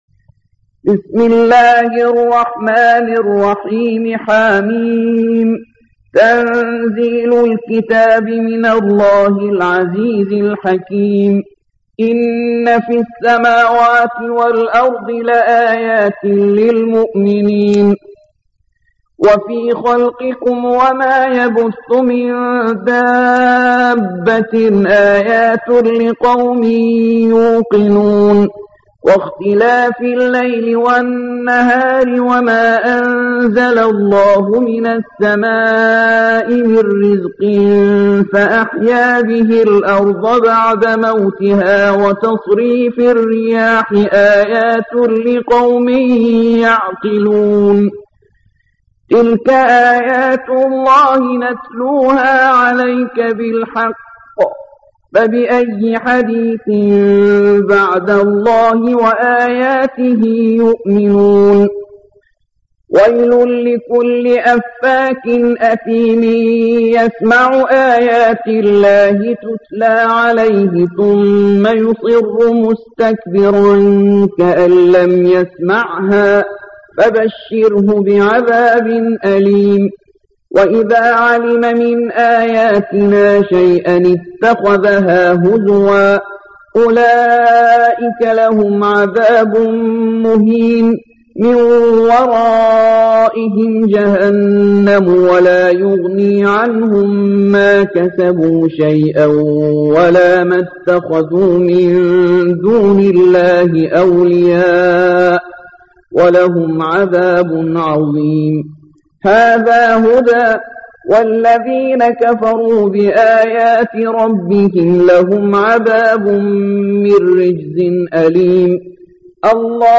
45. سورة الجاثية / القارئ